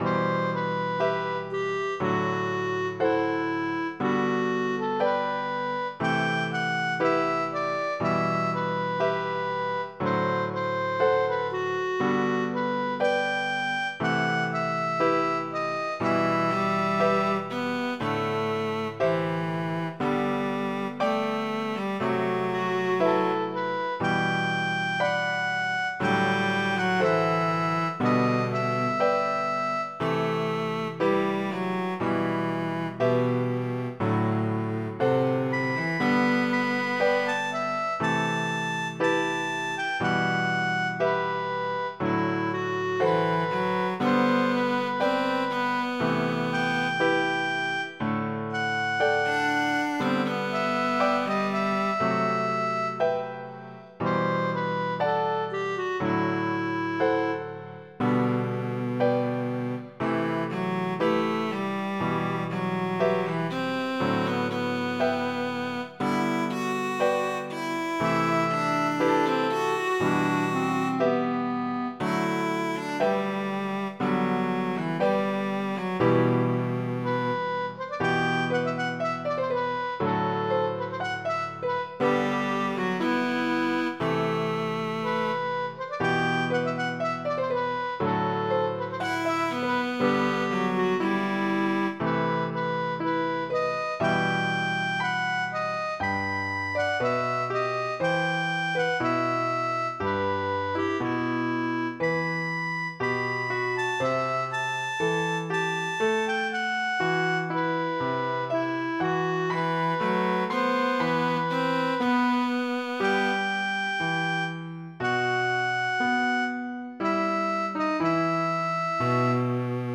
mp3-Aufnahme: mit midi Instrument